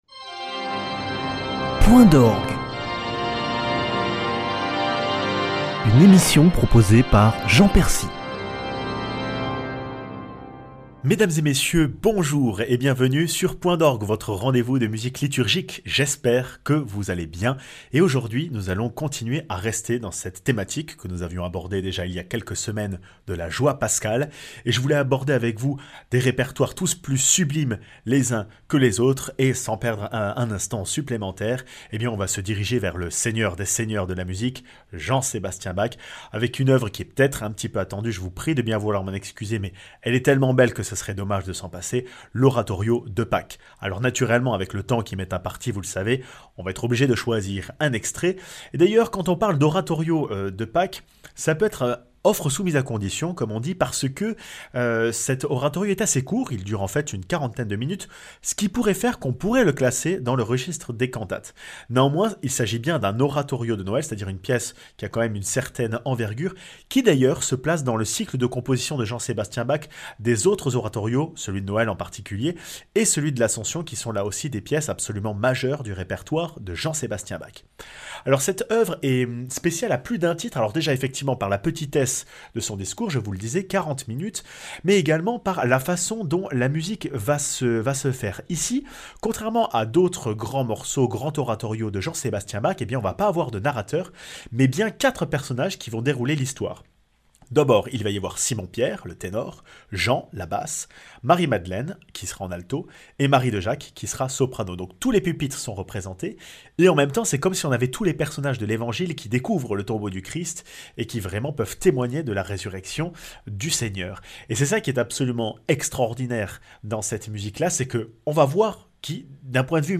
Instrument de prédilection de la joie, découvrez la valeur ajoutée des trompettes au chant sacré (Oratorio de Pâques de Bach, Marche des Marseillois de Balbastre et Sanctus de la messe Salve Regina de Langlais)